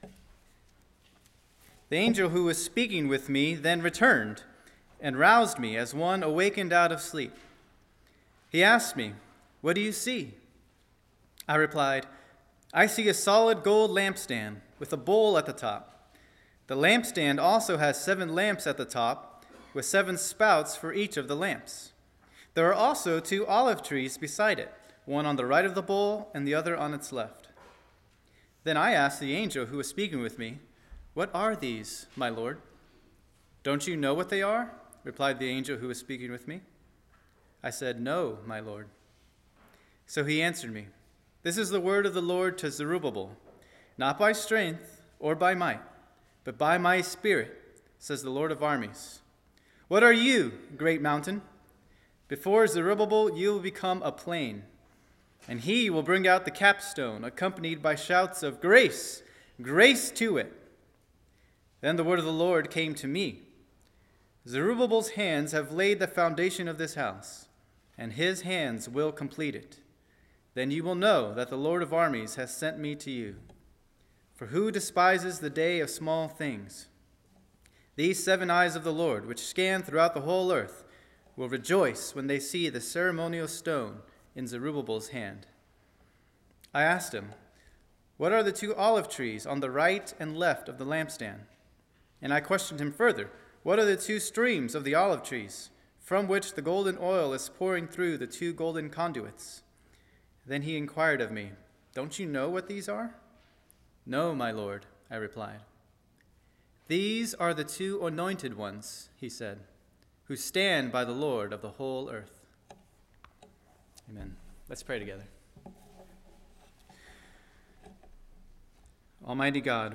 Sermon
2023 at First Baptist Church in Delphi, Indiana.